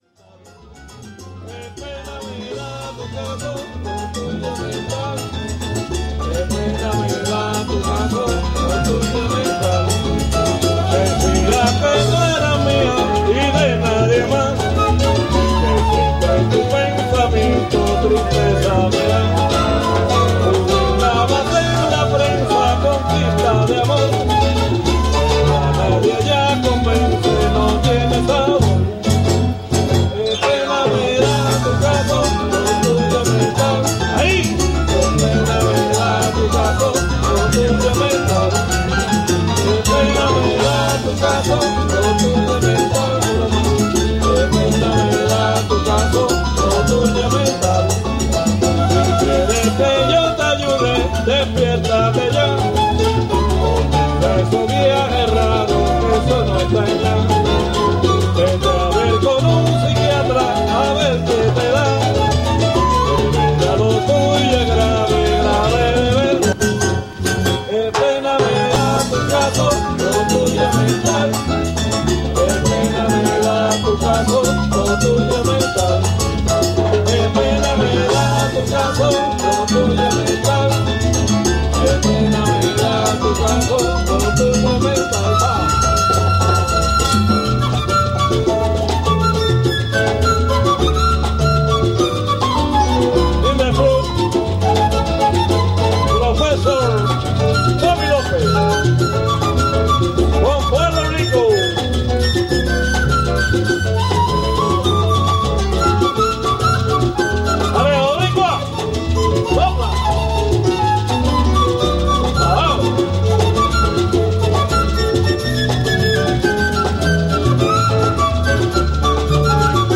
smooth latin jazz music with life, passion and excitement